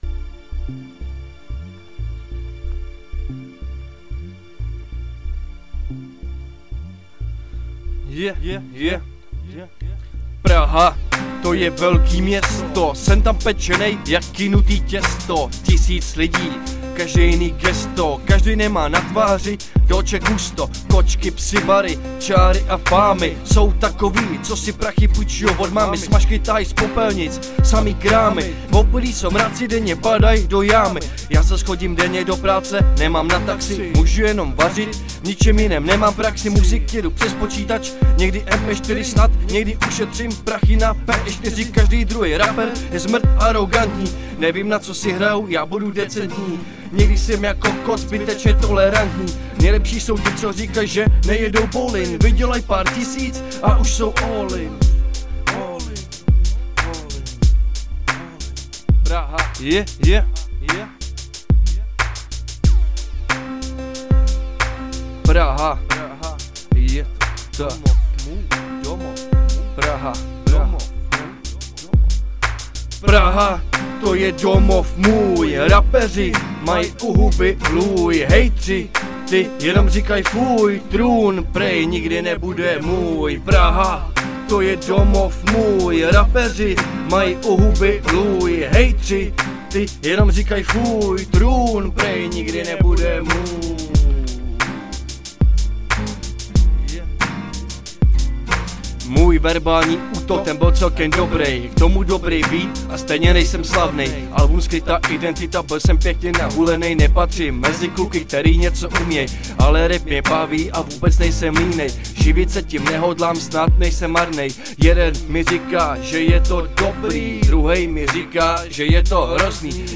Žánr: Hip Hop/R&B